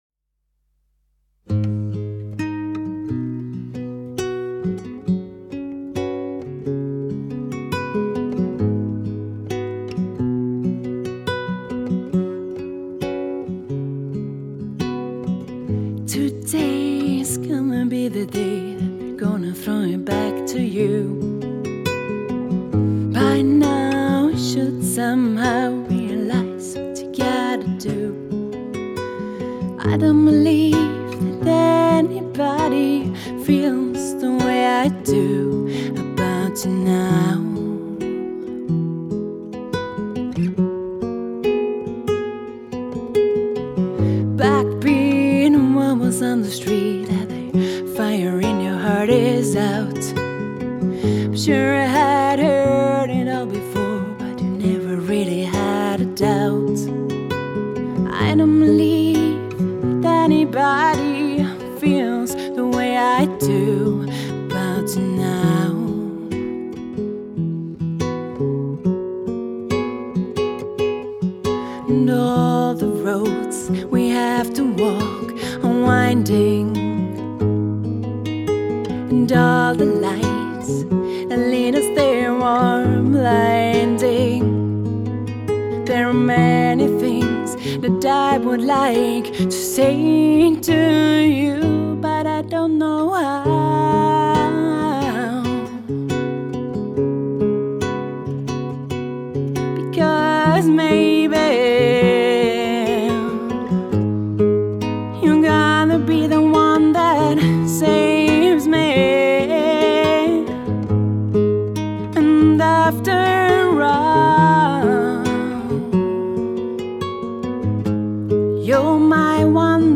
Vocals
Guitar